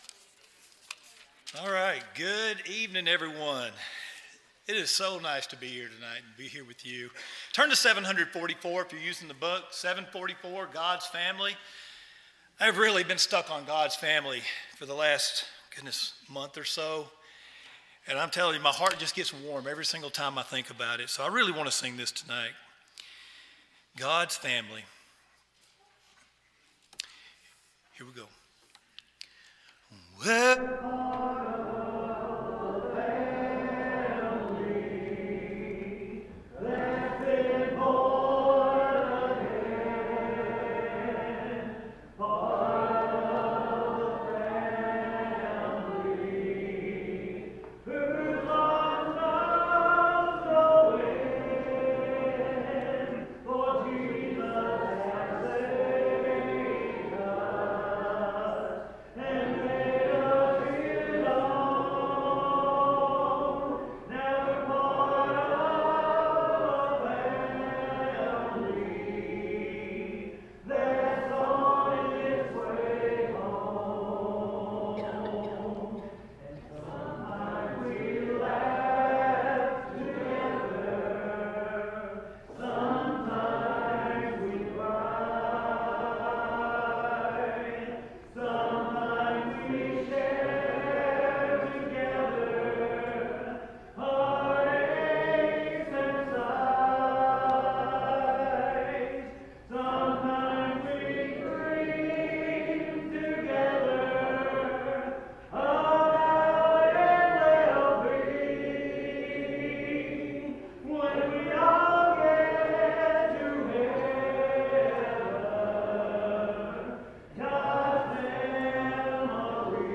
English Standard Version Series: Sunday PM Service